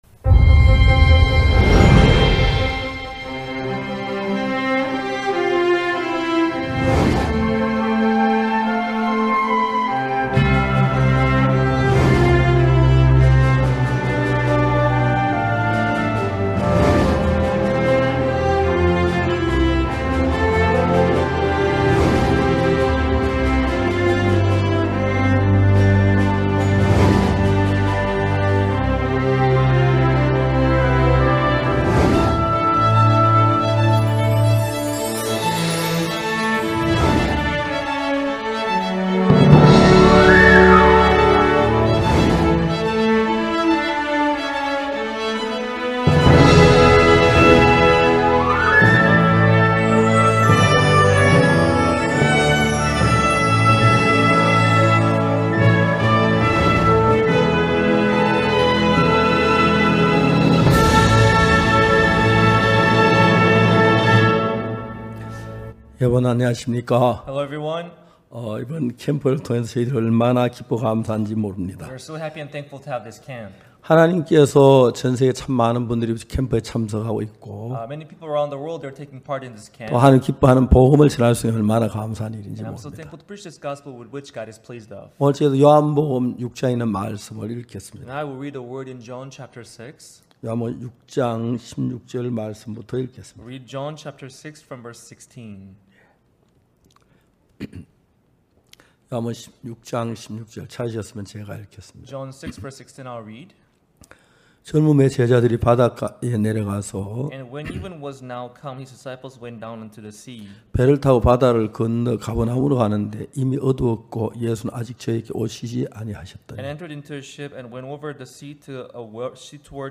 매해 여름과 겨울, 일 년에 두 차례씩 열리는 기쁜소식선교회 캠프는 아직 죄 속에서 고통 받는 사람들에게는 구원의 말씀을, 일상에 지치고 마음이 무뎌진 형제자매들에게는 기쁨과 평안을 전하고 있습니다. 매년 굿뉴스티비를 통해 생중계 됐던 기쁜소식 선교회 캠프의 설교 말씀을 들어보세요.